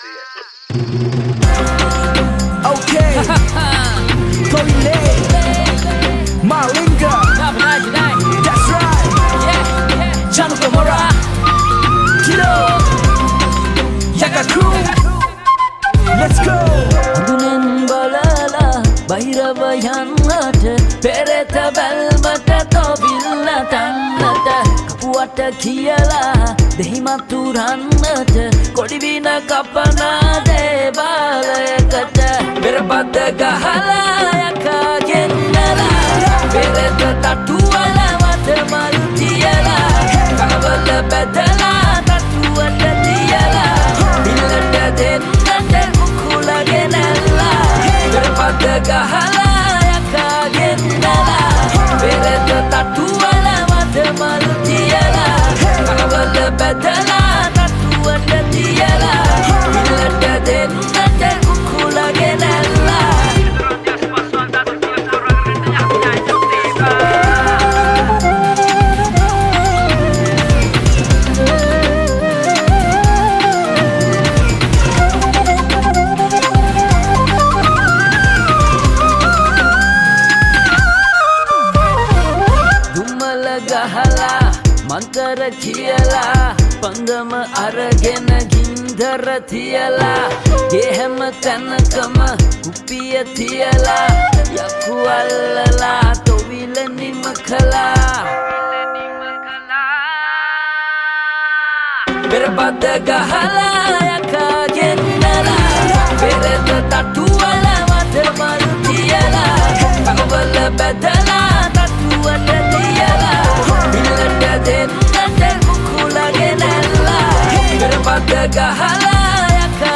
sinhala rap